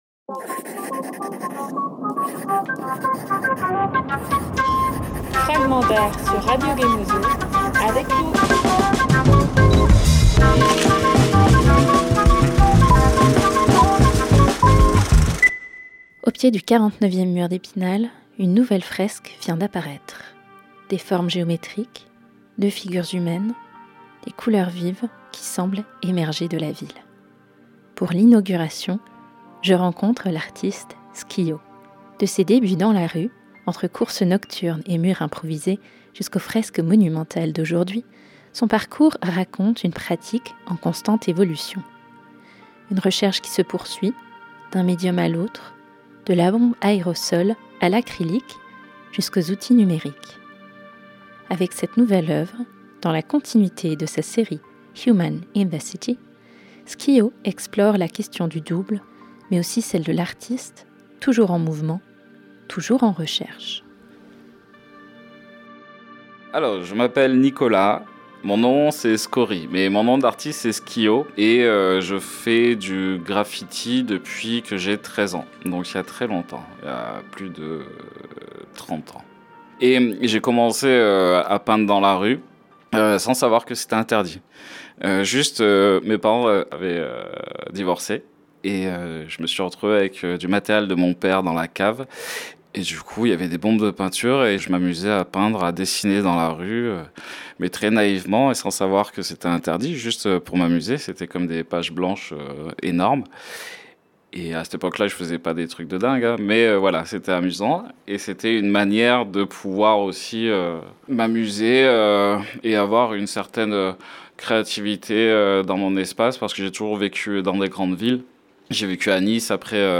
De ses débuts dans la rue aux fresques monumentales, il revient sur son parcours, ses influences, et cette nécessité constante de faire évoluer sa pratique, entre liberté de création et contraintes du monde de l’art. Une conversation autour du double, de l’évolution, et de cette figure de l’artiste toujours en mouvement.